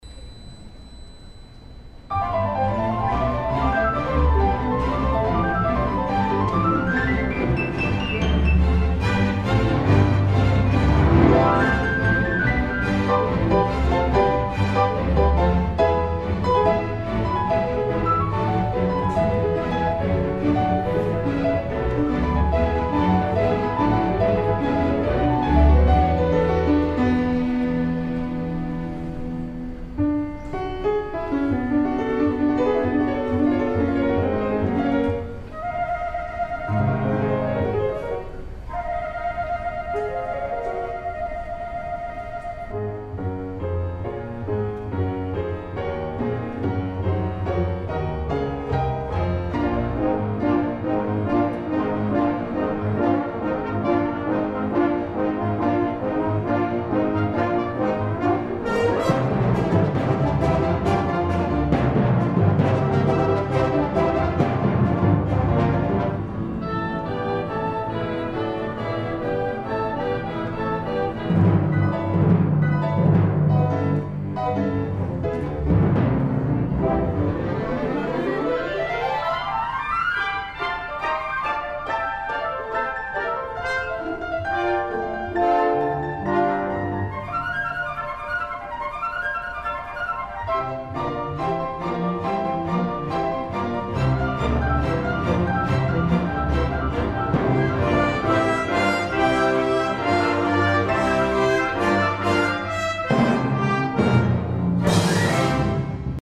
Divertimento para piano y orquesta Joaquín Gutierrez Heras